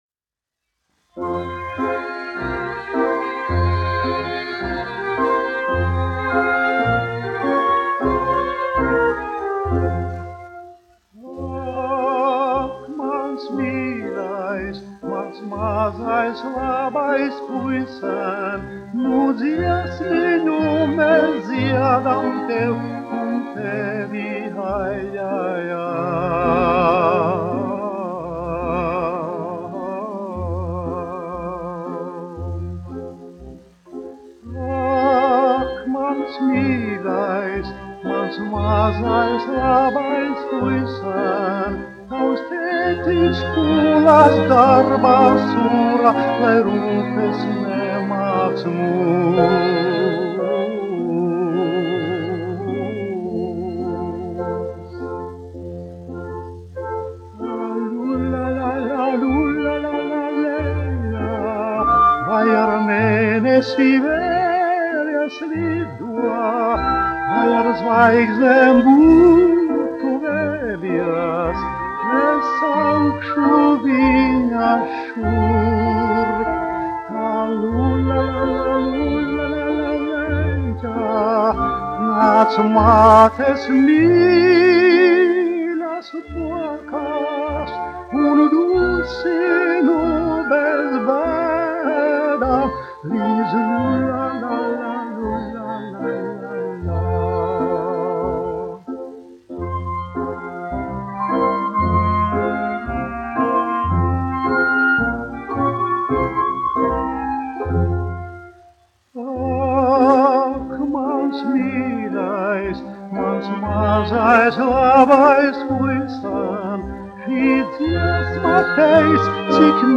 1 skpl. : analogs, 78 apgr/min, mono ; 25 cm
Dziesmas (augsta balss) ar orķestri
Skaņuplate